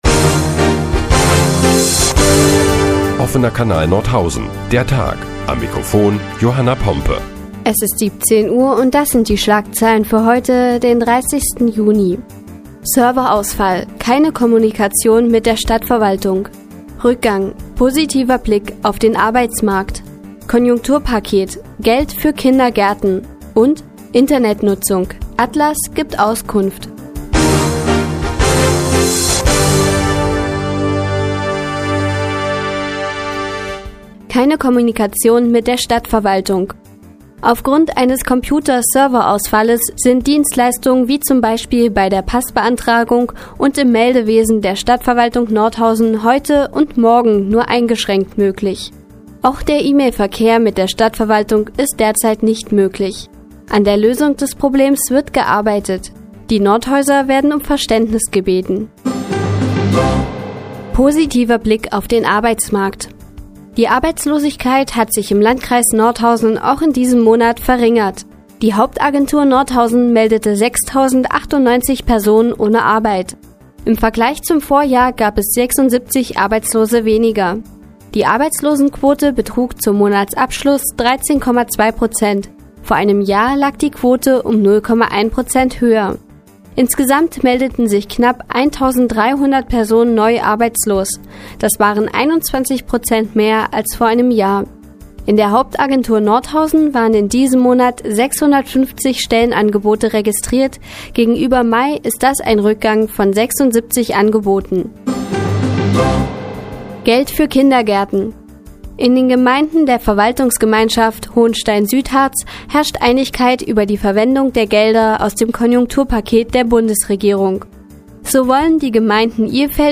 Die tägliche Nachrichtensendung des OKN ist nun auch in der nnz zu hören. Heute geht es unter anderem um einen positiven Blick auf dem Arbeitsmarkt und Geld für Kindergärten.